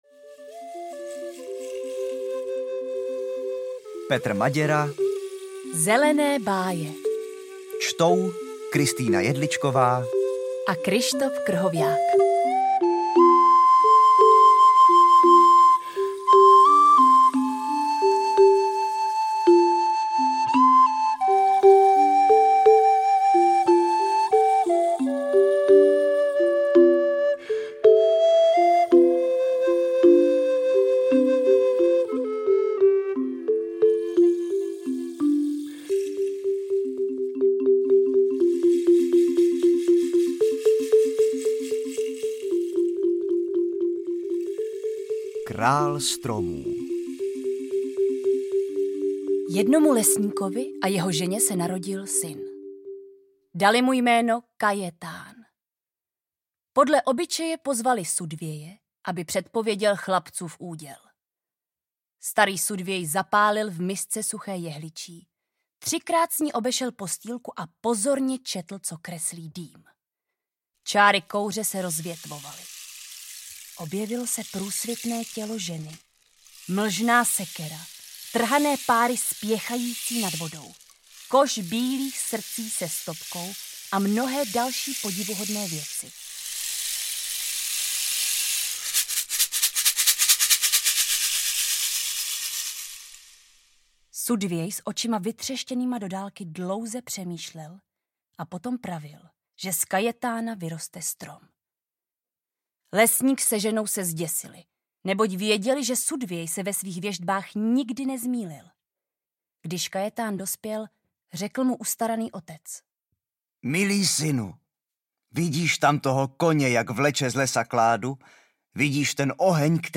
Zelené báje audiokniha
Ukázka z knihy